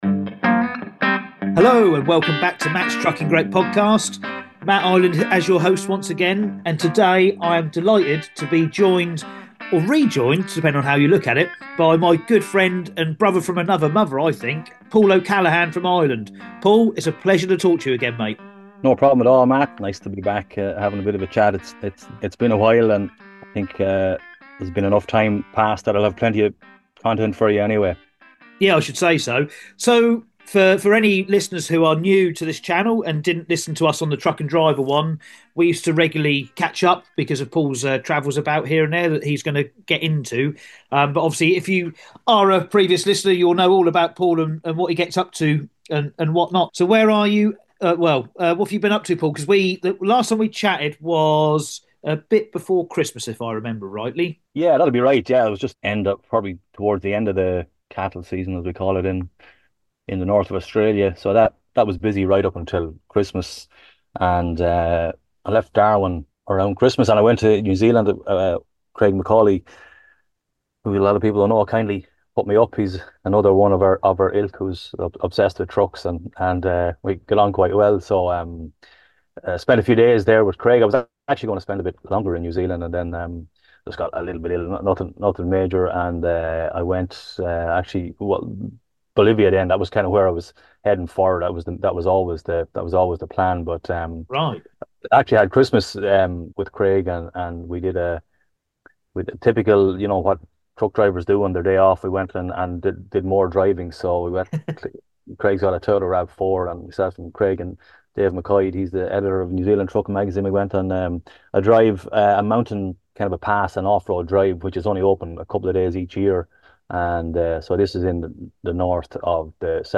This is the podcast for truck drivers, hosted by and featuring interviews from people in the industry.